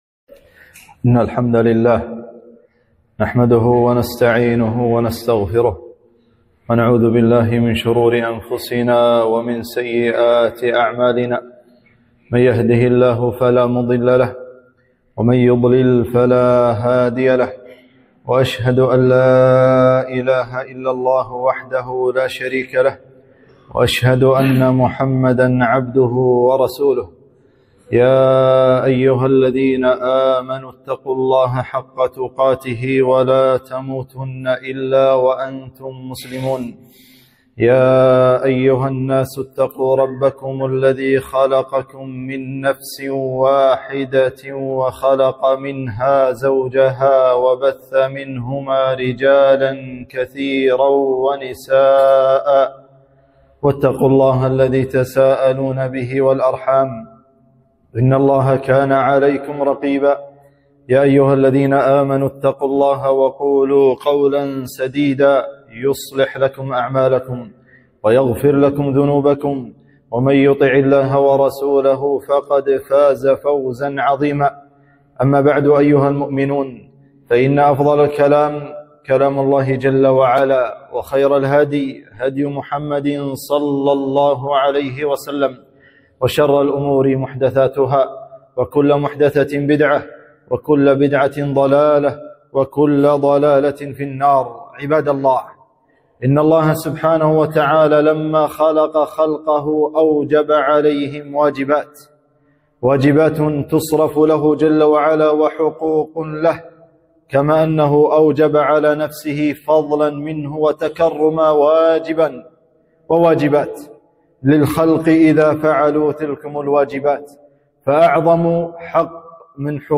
خطبة - حق الله على العباد والخوف من العقاب